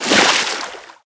Sfx_creature_penguin_dive_shallow_02.ogg